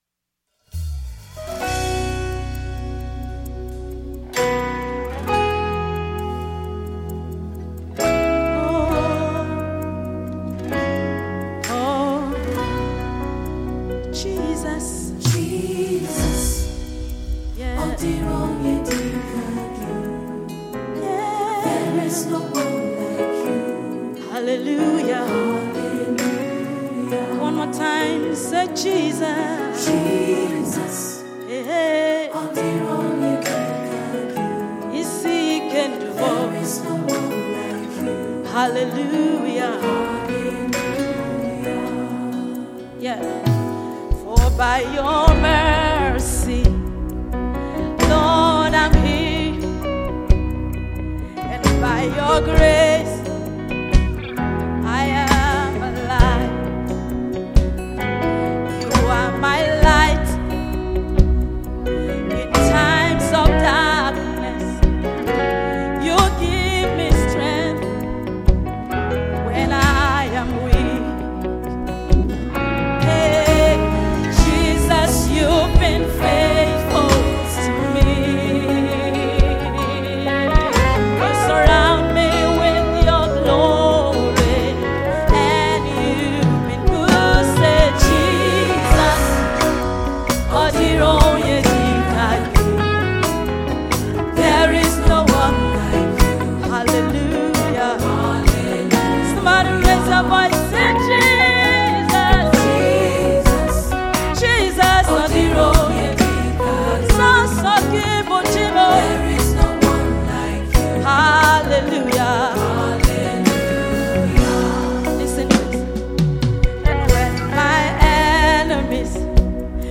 a deep worship song
Recorded live at Greenland sound factory.